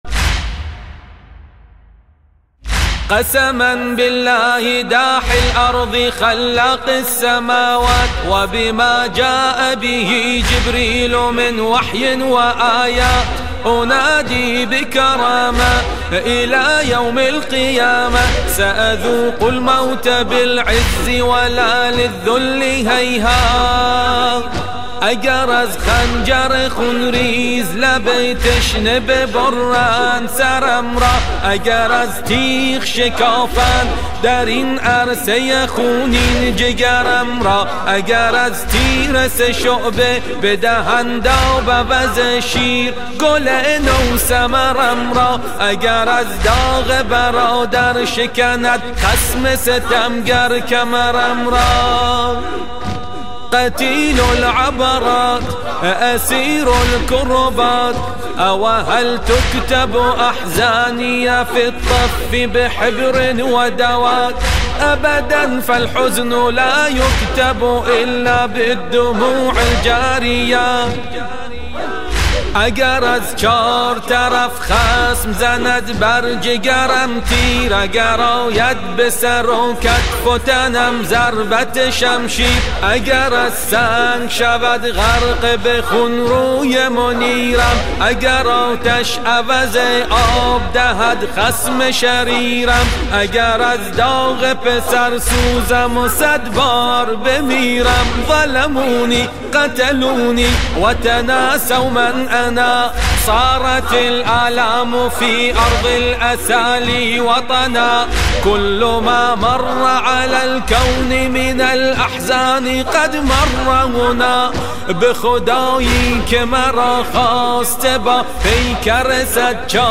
مداحی عربی - فارسی